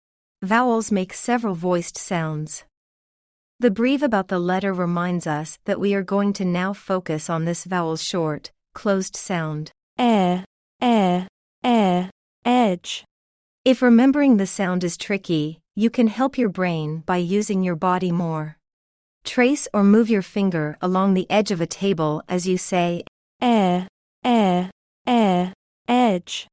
E-edge-lesson-AI.mp3